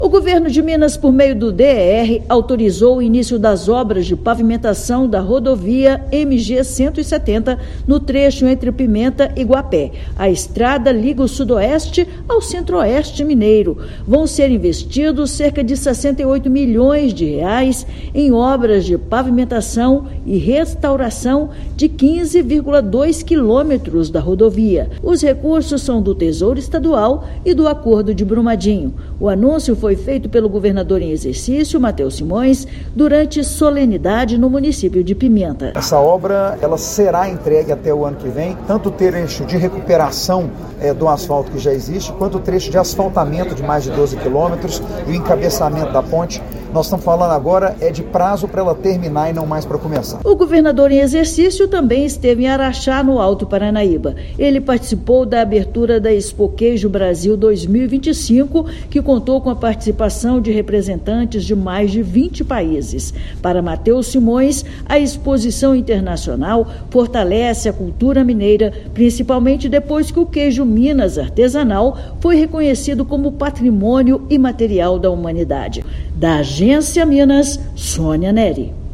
[RÁDIO] Governo de Minas autoriza pavimentação da rodovia MG-170 entre Pimenta e Guapé
Asfaltamento do trecho é aguardado há mais de 30 anos pelos moradores e vai impulsionar o desenvolvimento econômico e o turismo da região. Já em Araxá, o governador em exercício de Minas Gerais abriu a ExpoQueijo Brasil 2025. Ouça matéria de rádio.